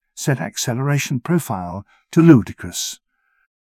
jankboard/client/public/static/voices/en-UK/set-acceleration-profile-ludicrous.wav at 6d5ef6809ad06cad6a4be8c95bccb34e7a765f9f